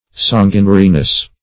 Meaning of sanguinariness. sanguinariness synonyms, pronunciation, spelling and more from Free Dictionary.
sanguinariness.mp3